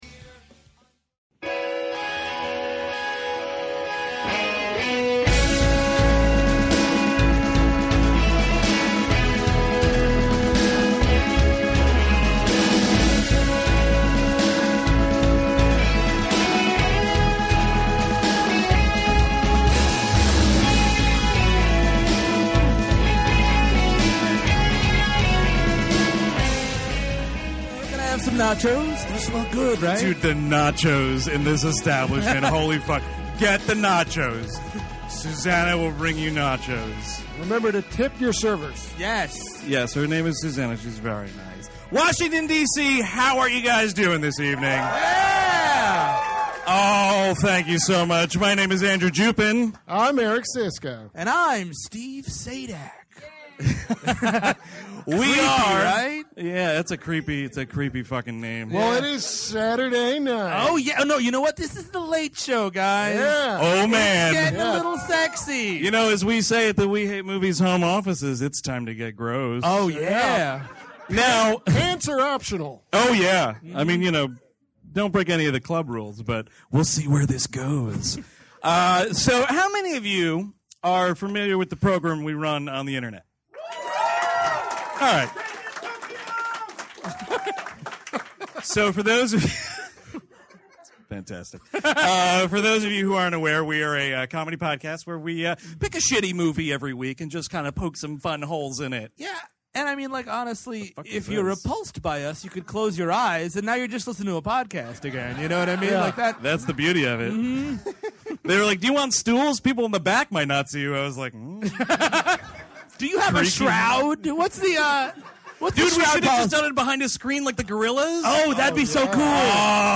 S6 Ep248: Episode 248 - Waterworld (Live!)